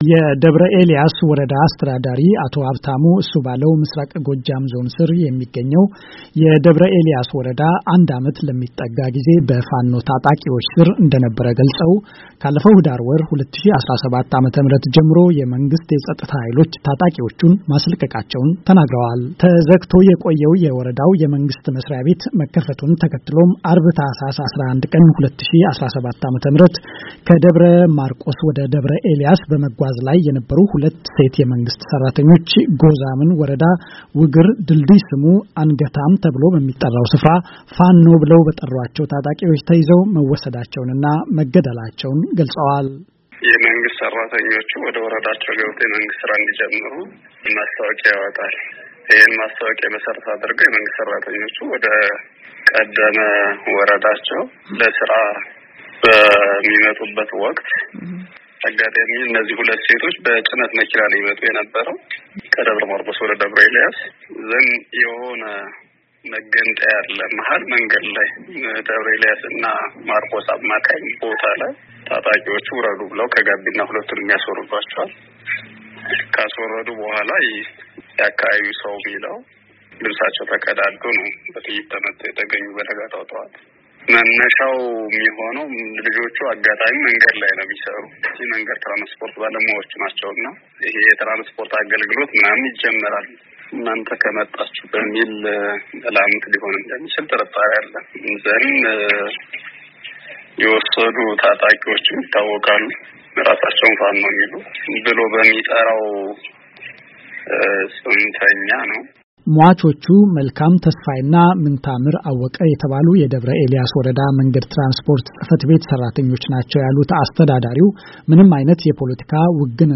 ዜና
በአሜሪካ ድምጽ ዘጋቢዎች የተጠናቀረውን ዘገባ ከተያያዘው ፋይል ይከታተሉ፡፡